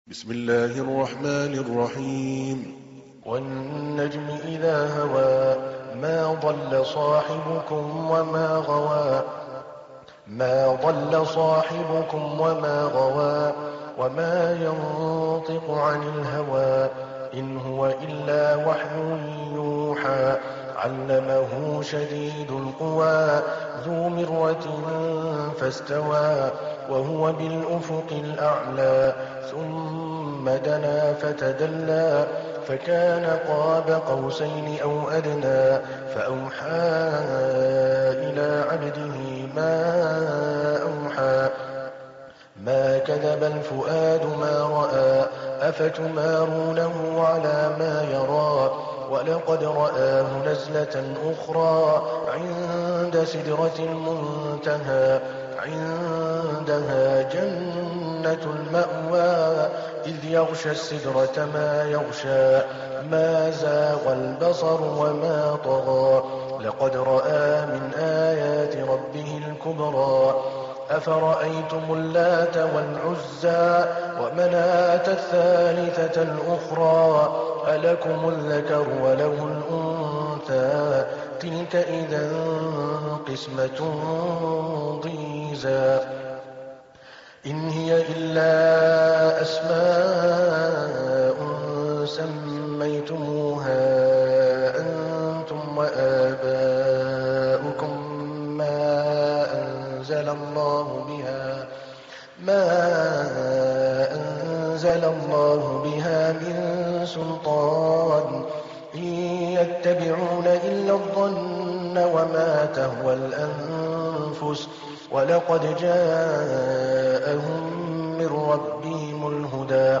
تحميل : 53. سورة النجم / القارئ عادل الكلباني / القرآن الكريم / موقع يا حسين